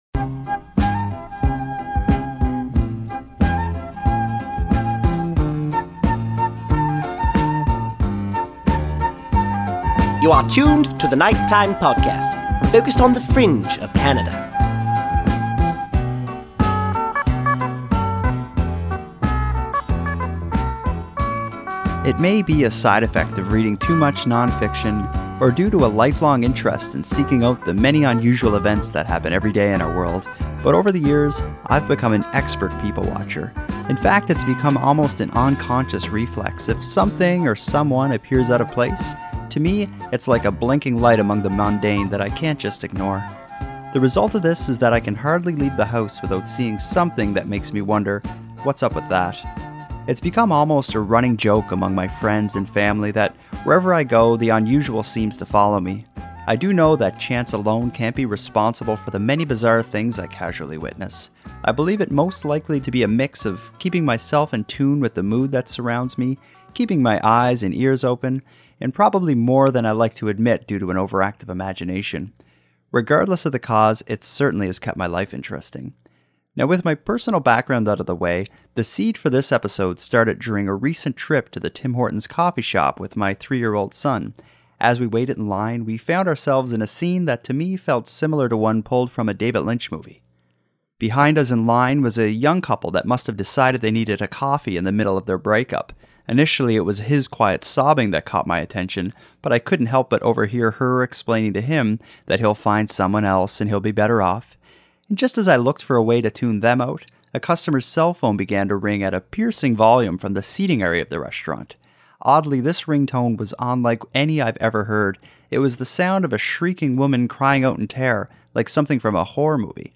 and two Night Time Podcast listeners all providing their unique stories.